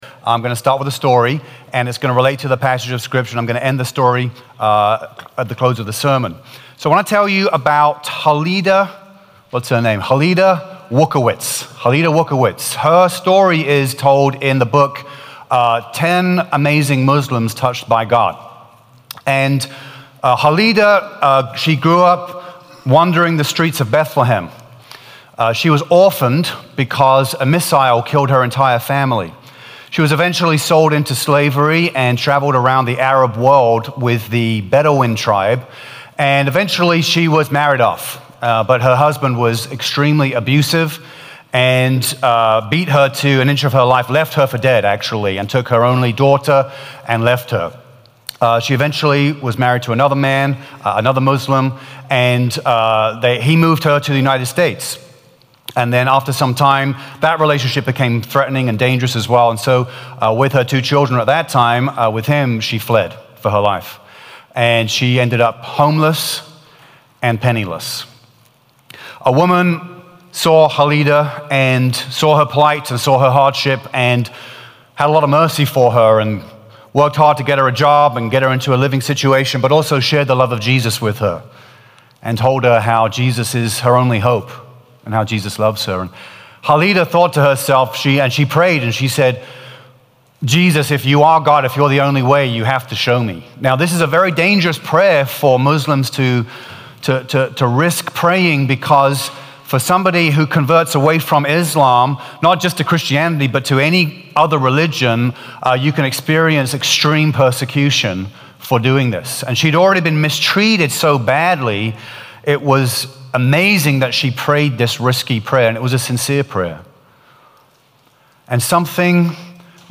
September-29-Full-Sermon-Podcast.mp3